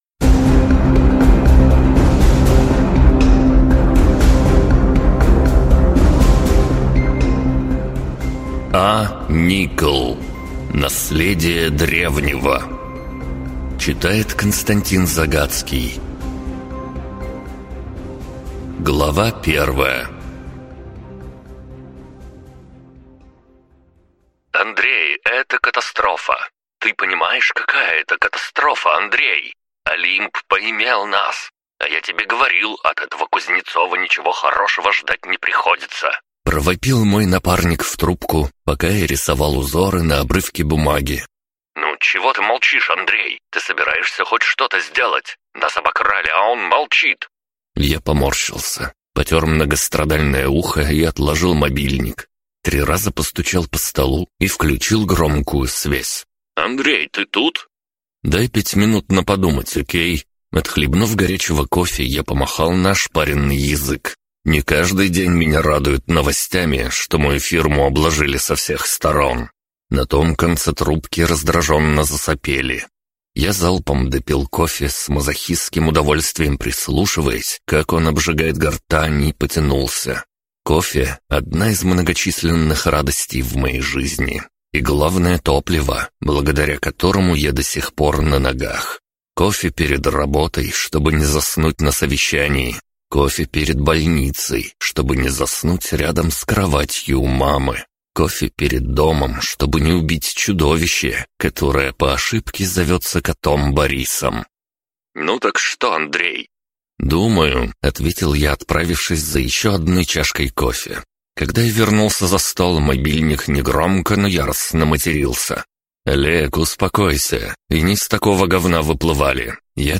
Слушать аудиокнигу А.Никл. Наследие Древнего. Я умер и попал в средневековый магический мир, в тело самого могущественного существа на планете.